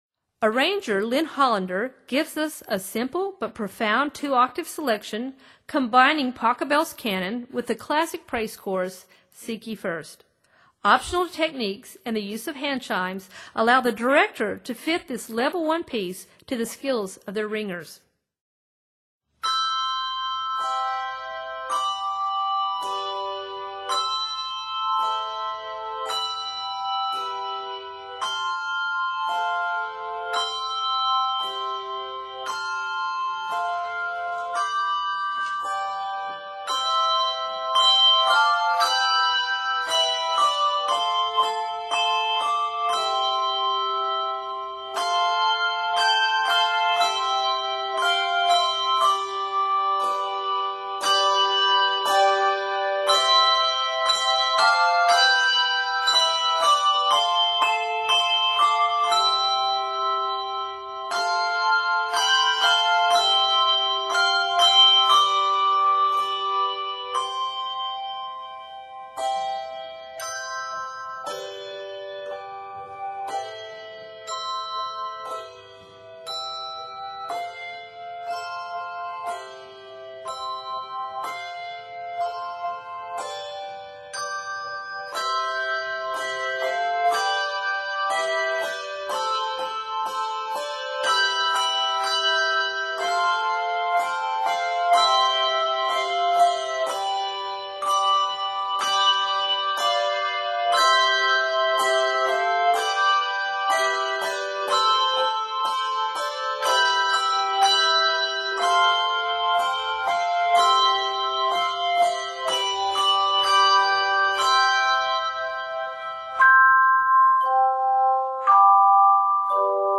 praise song
A total of 57 measures, this setting is scored in C Major.